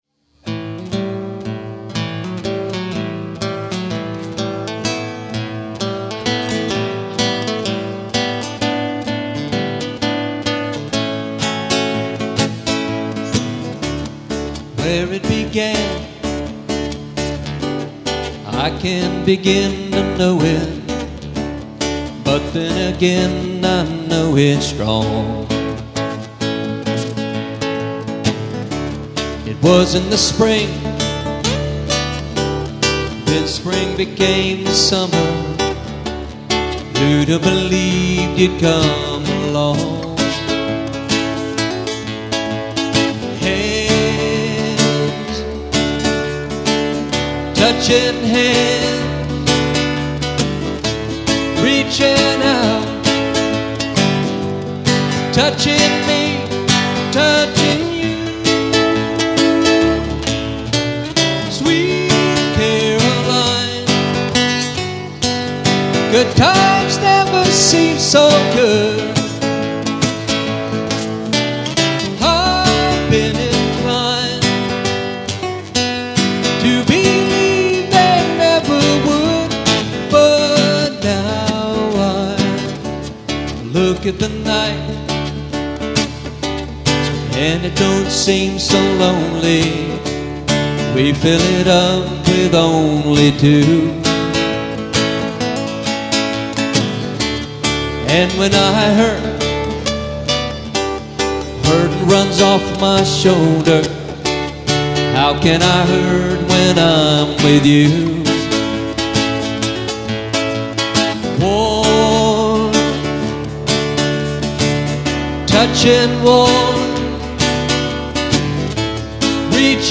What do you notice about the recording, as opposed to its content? (recorded live)